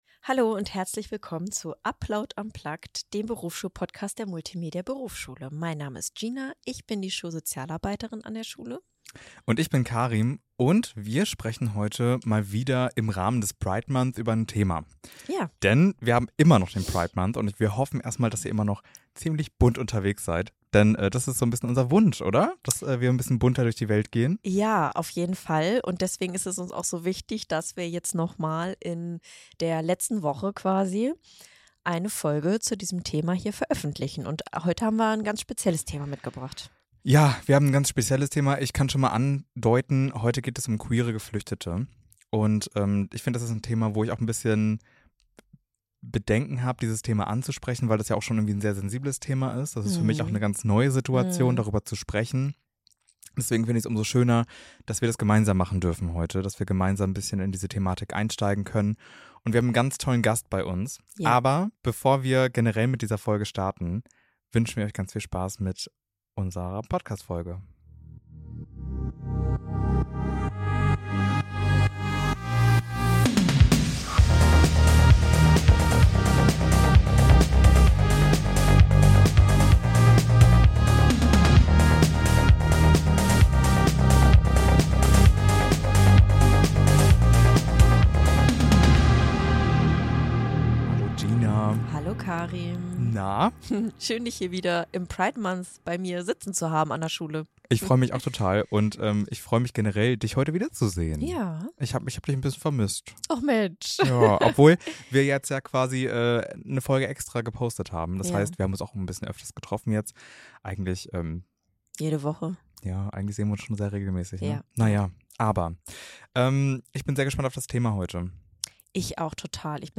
und einem jungen queeren Menschen mit Fluchterfahrung sprechen wir über Heimatverlust, Angst, Hoffnung und das mutige Ankommen in einem neuen Leben. Eine Folge über Mut, Selbstbestimmung, Ausgrenzung und die Kraft von Akzeptanz und Unterstützung.